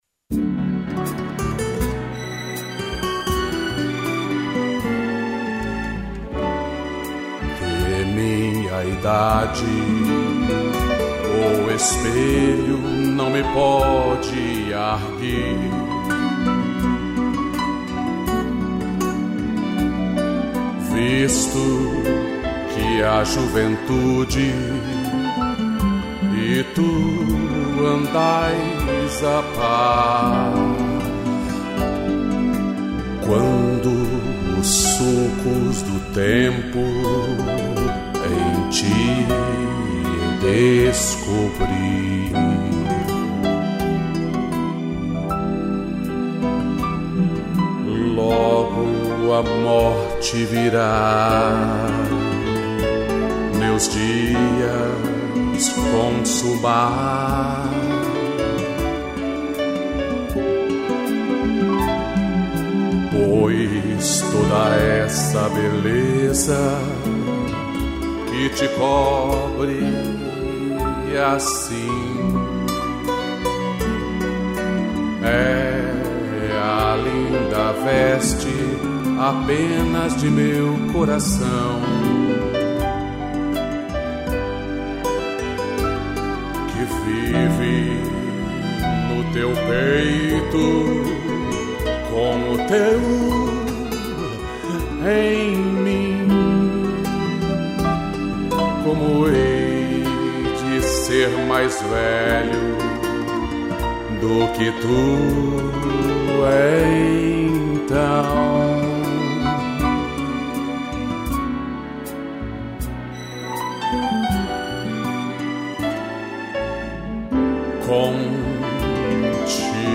interpretação e violão
piano